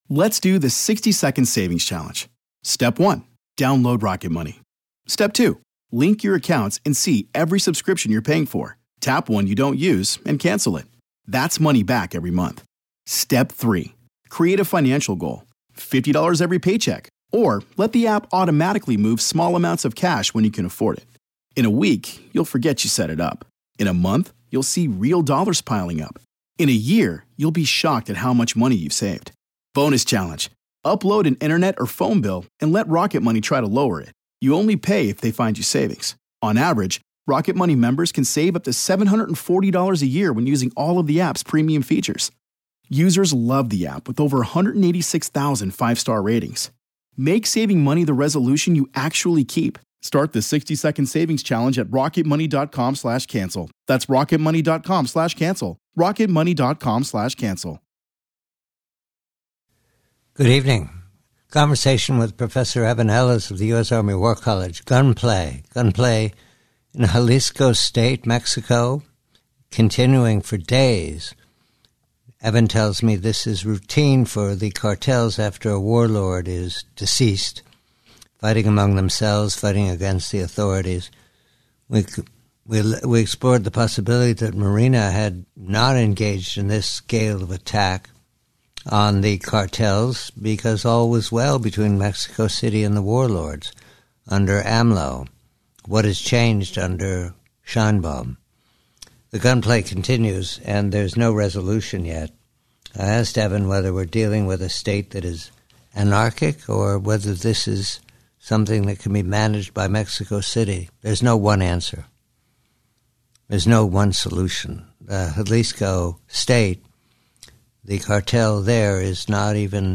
1912 WILLIAM JENNINGS RBYAN SPEECH